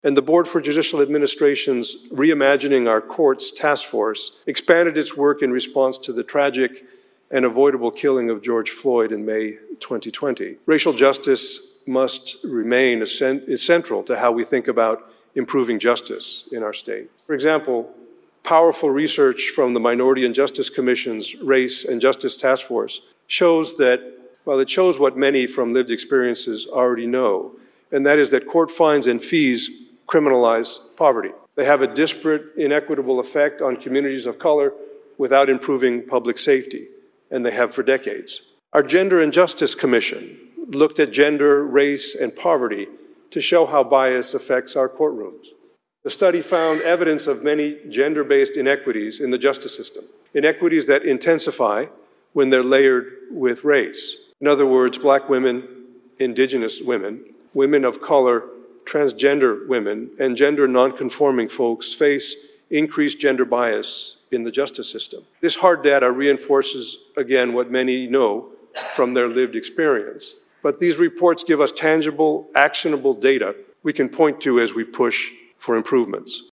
WA Supreme Court Chief Justice González Delivers State of the Judiciary Address (Listen/Watch)